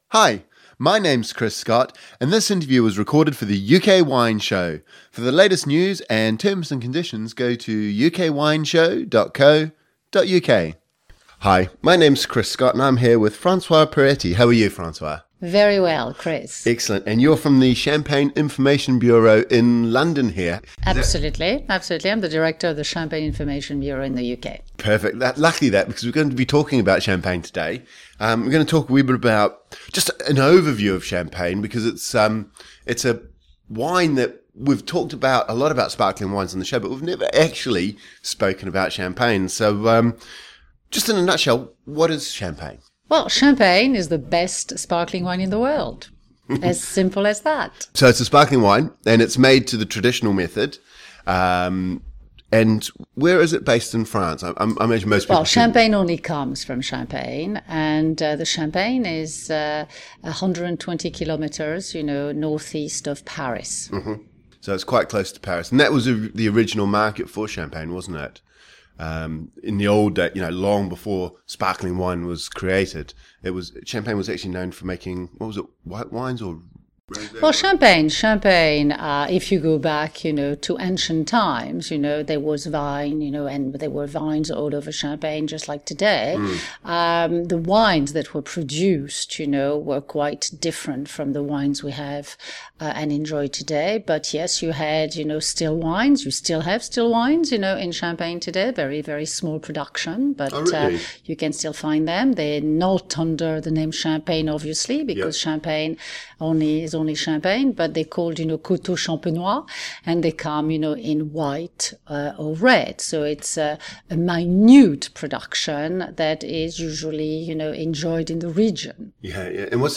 In this interview we hear about the Champagne region and its history as well as how the method of Champagne production has evolved over the years. We also touch on the production of pink Champagne and compare the two methods of skin contact vs blending white and red.